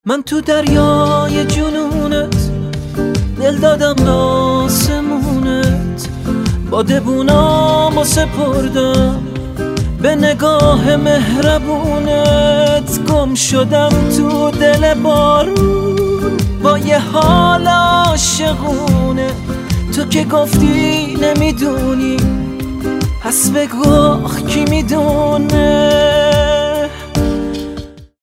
رینگتون احساسی و باکلام